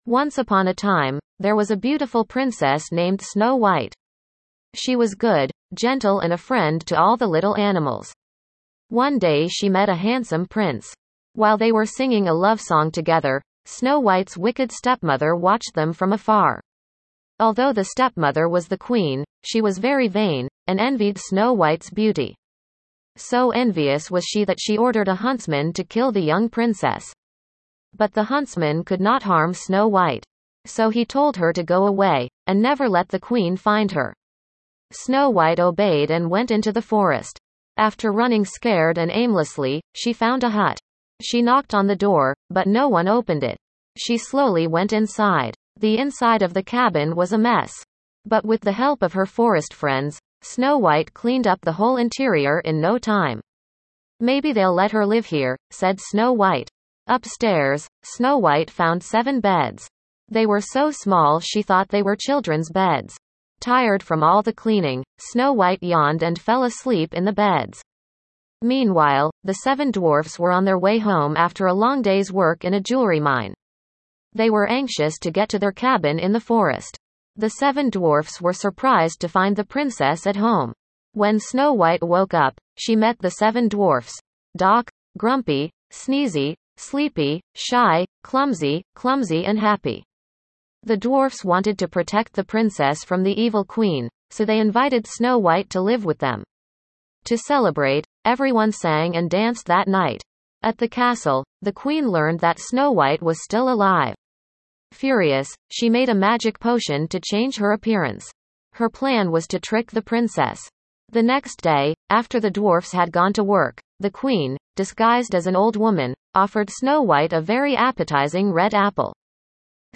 Enjoy it! Here’s the audio story!